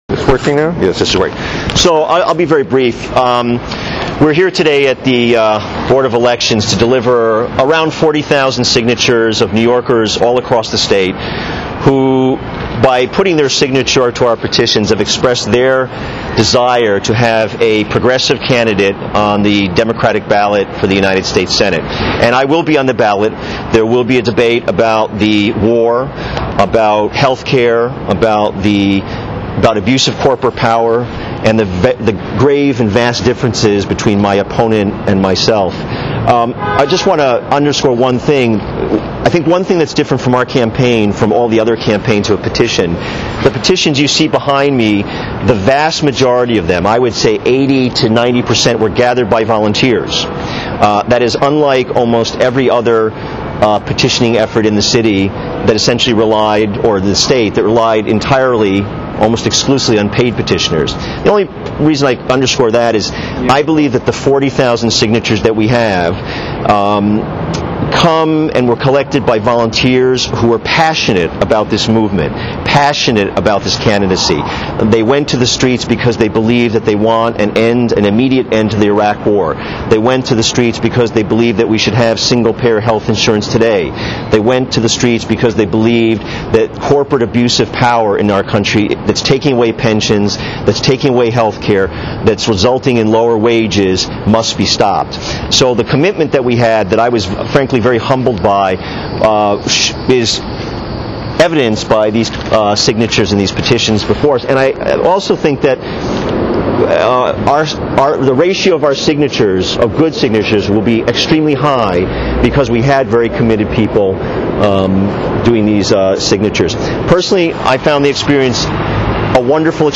Press Conference at Board of Elections